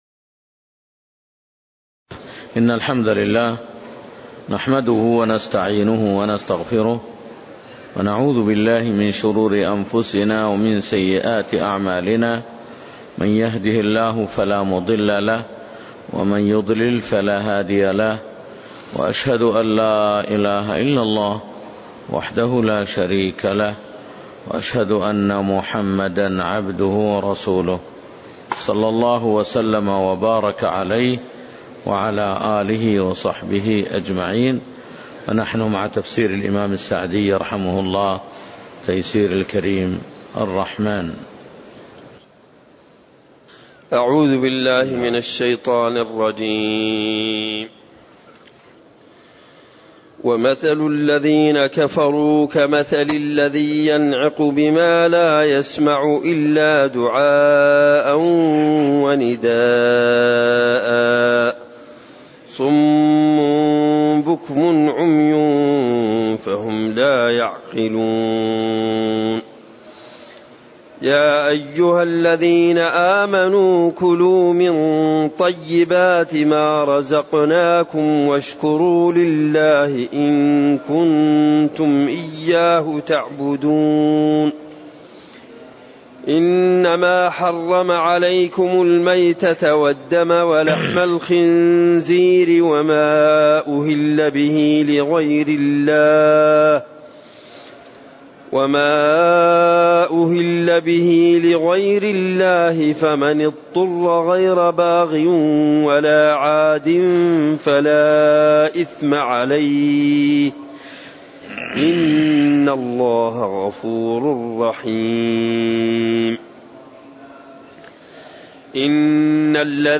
تاريخ النشر ١٠ شعبان ١٤٣٨ هـ المكان: المسجد النبوي الشيخ